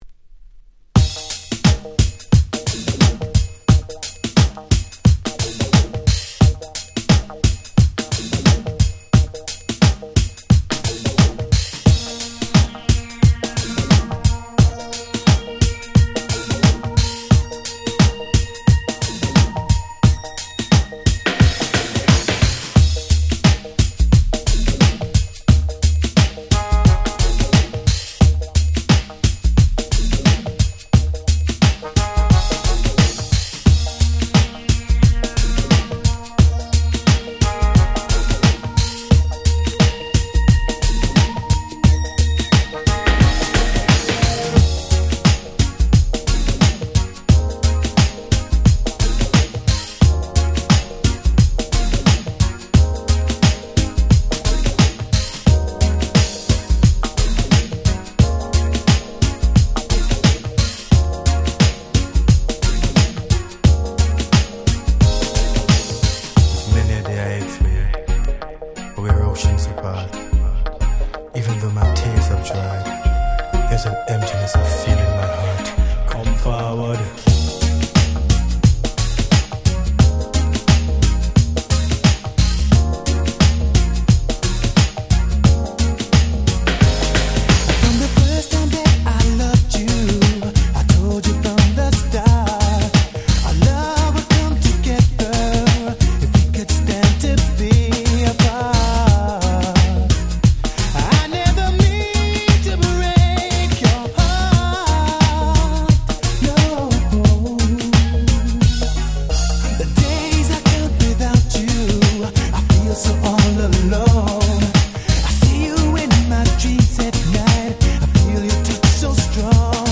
ほんのりReggae風味の歌声に切ないメロディー、当時地味にヒットした1枚。